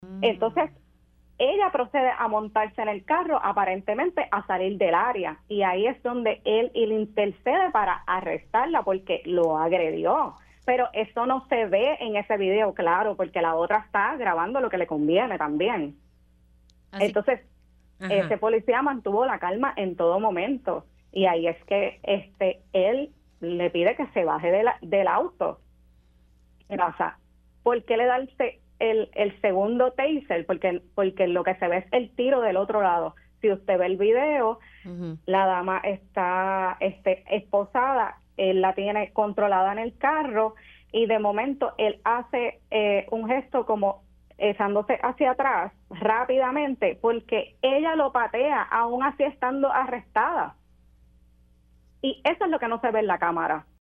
Una mujer, que fue testigo del incidente y reside en la zona, indicó que varias personas “títeres” han provocado situaciones similares en la comunidad debido a un dispensario en el local de Guaynabo que atrae a una gran cantidad de personas con sus bajos precios.